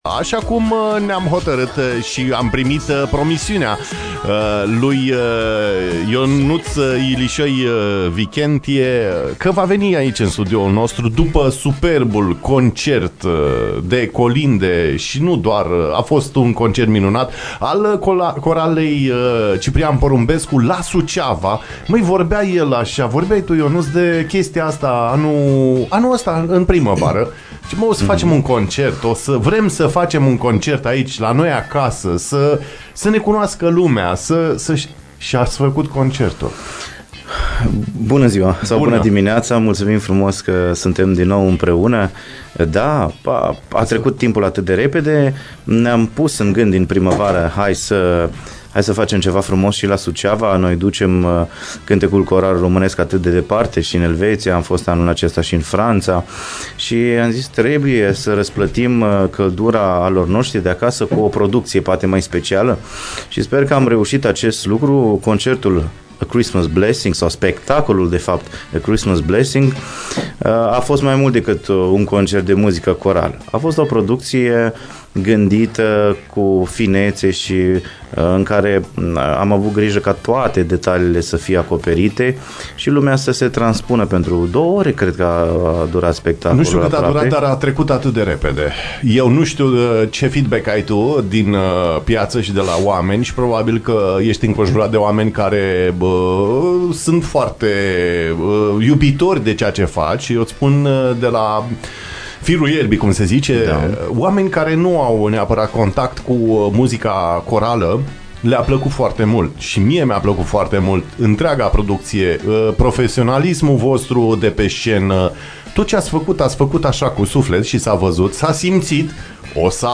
Prezent în studioul IMPACT FM, în această dimineață